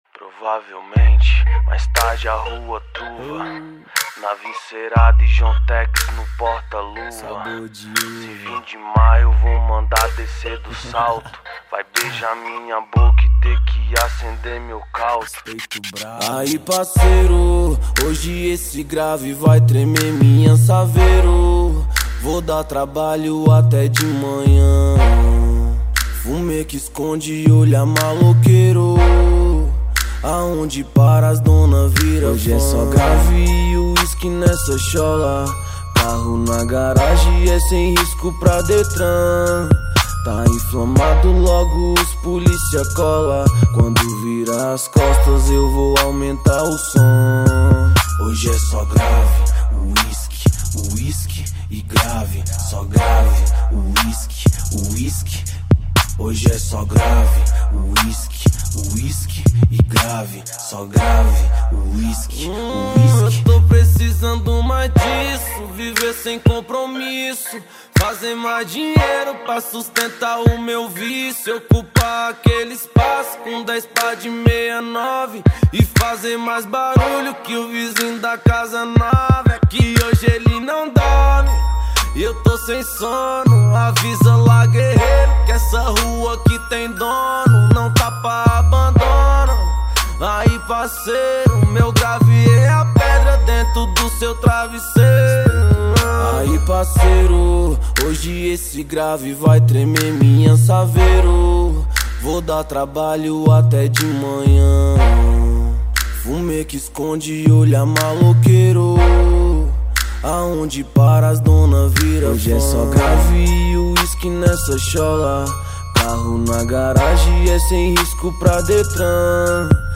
2024-03-03 09:46:05 Gênero: Hip Hop Views